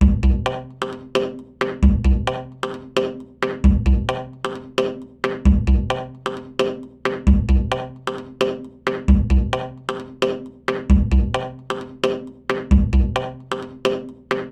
Percutube
Le percutube est composé de huit tubes de bambou ouverts aux extrémités et de différentes longueurs. Il couvre une octave de portée correspondant à la gamme de do majeure.
Le principe acoustique de cet instrument repose sur la mise en résonance de l’air contenu dans un tube dont on percute une extrémité.
Cet orifice a été rétréci par une membrane composite (bois, peau) qui permet le dégagement des harmonique.
SON-PERCUTUBE.mp3